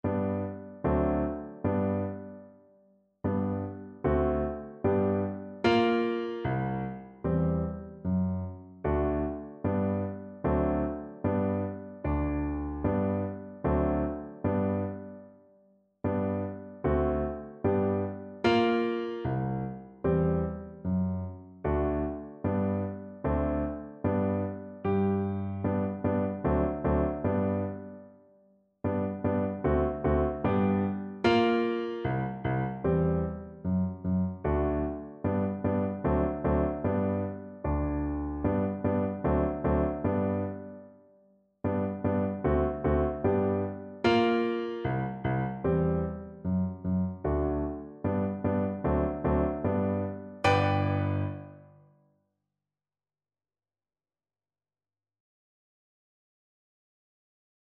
Free Sheet music for Soprano (Descant) Recorder
G major (Sounding Pitch) (View more G major Music for Recorder )
4/4 (View more 4/4 Music)
Moderato
Traditional (View more Traditional Recorder Music)